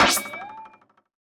otherHighJump.wav